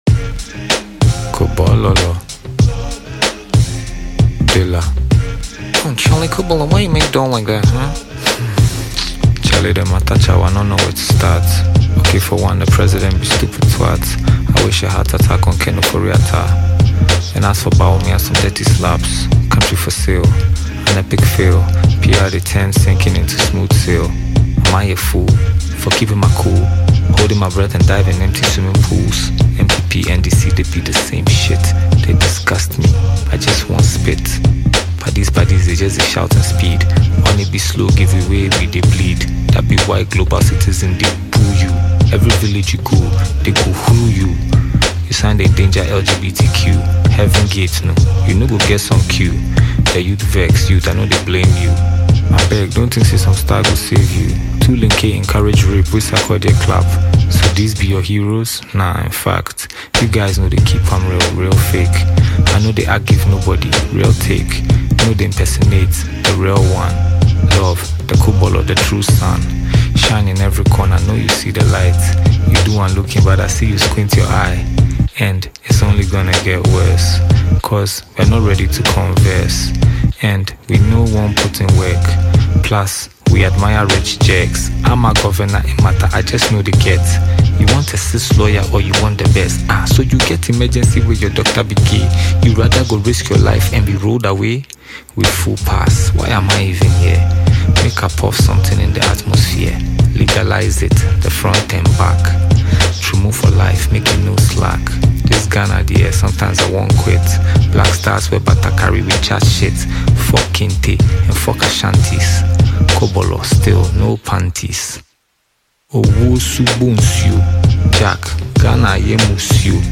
Ghanaian prolific rapper and Sensational Songwriter